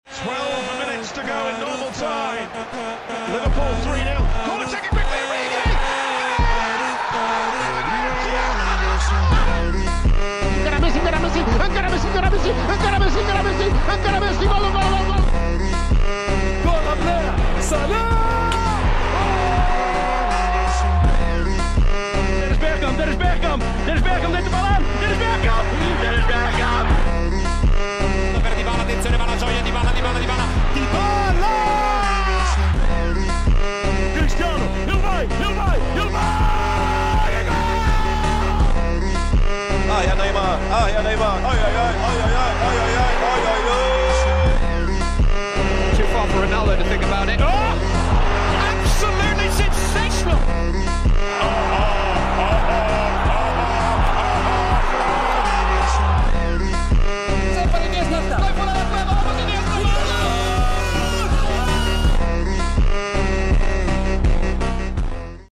Legendary commentary part2 🔥☠ ||